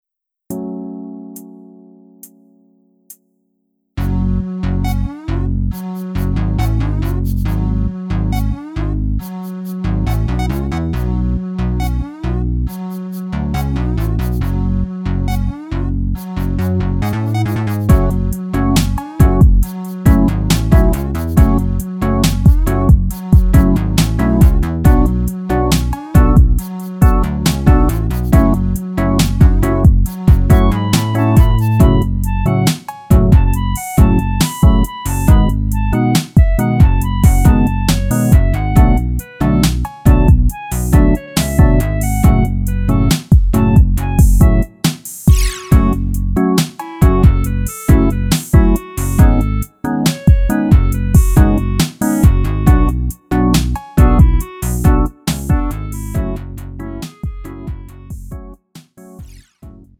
음정 원키 2:41
장르 구분 Lite MR